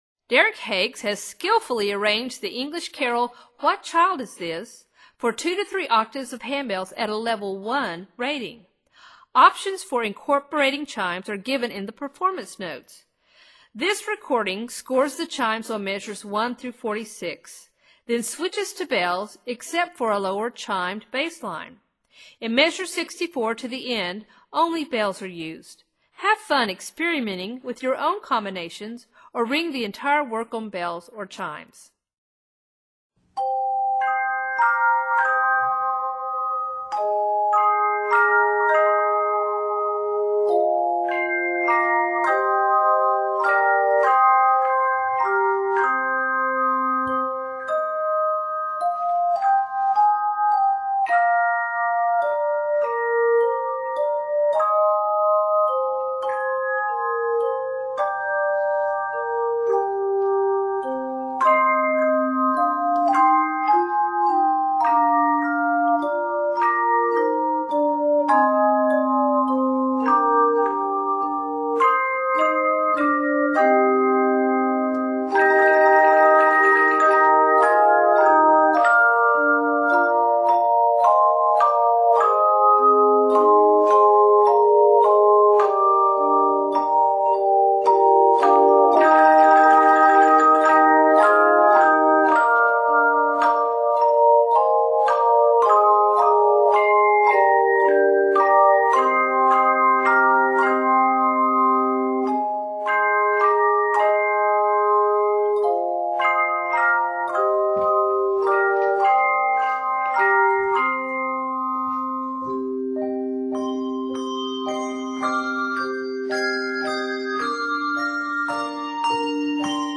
This reflective setting of the 16th century English melody
works equally well for bells or chimes or a mixture of both.